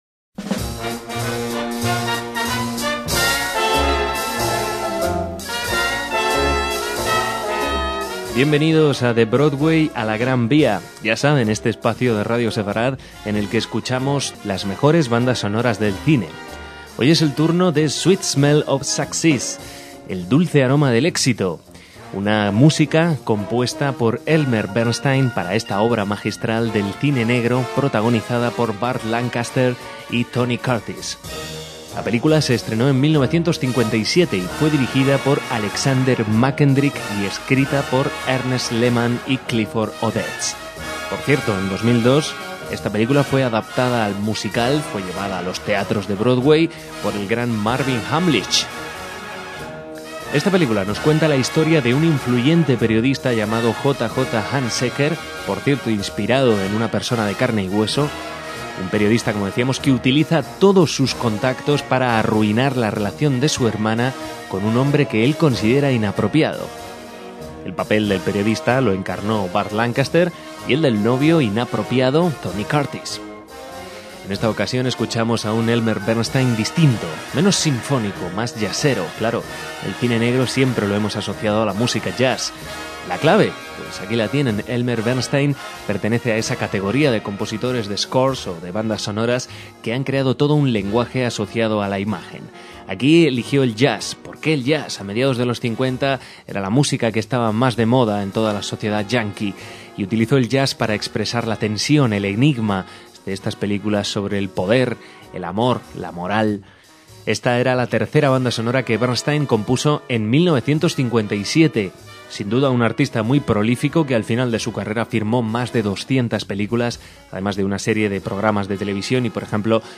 Su sonido emula el ambiente de Manhattan en los años 50.